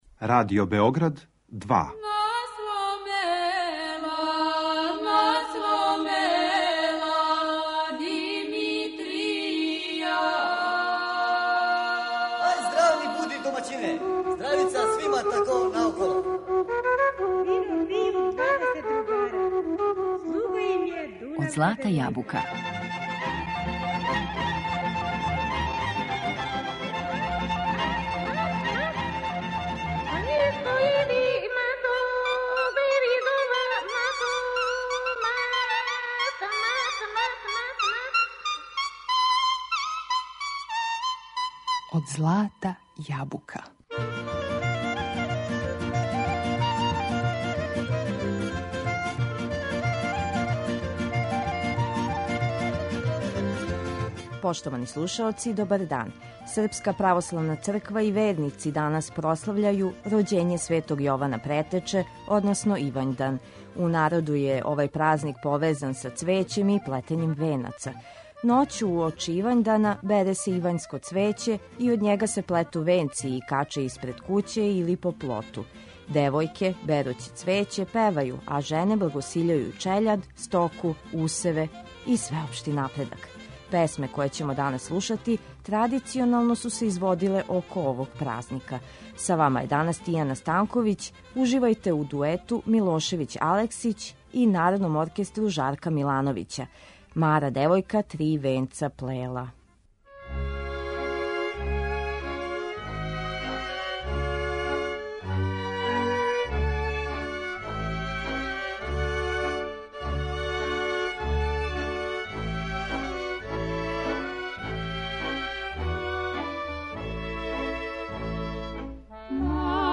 Песме које ћемо данас слушати традиционално су се изводиле у време овог празника.